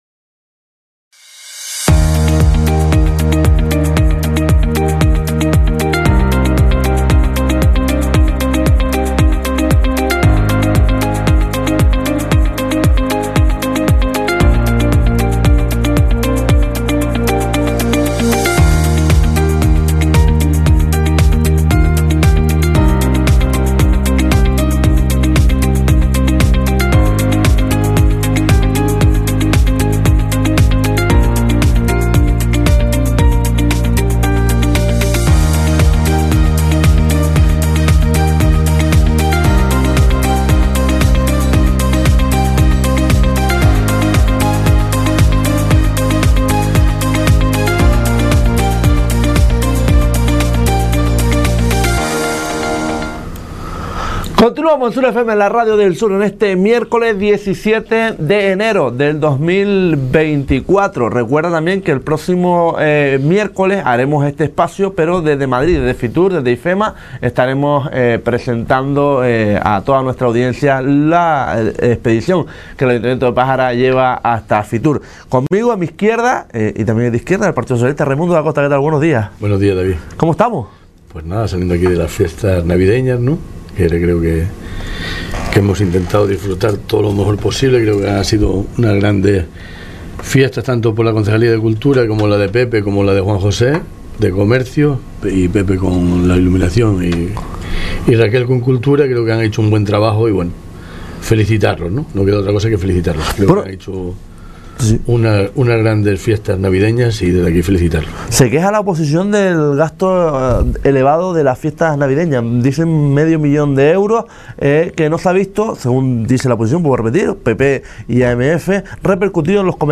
El pasado miércoles, día 17 de enero, nos visitaba el concejal de playas, seguridad y emergencia y régimen interior, Raimundo Dacosta, en el espacio de "El